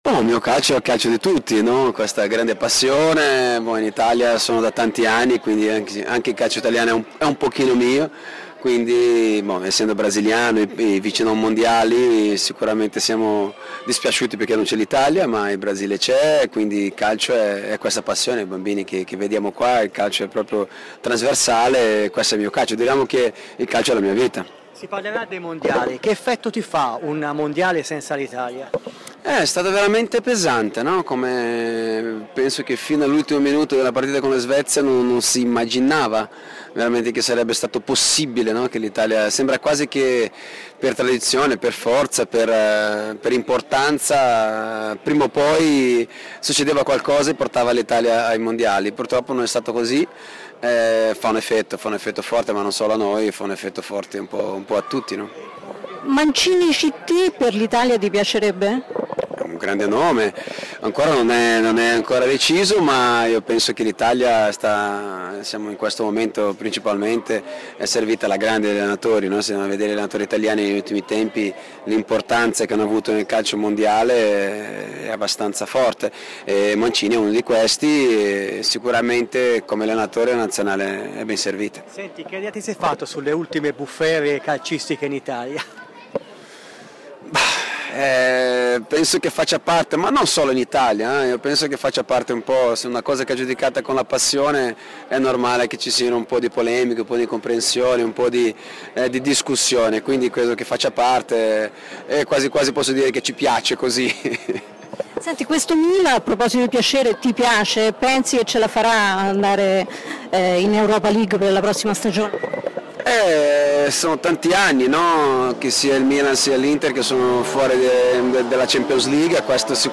Leonardo, a margine del Moby Dick Festival di Terranuova Bracciolini, ai microfoni di RMC Sport.